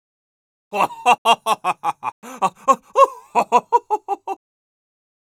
Scout_laughhappy02_es.wav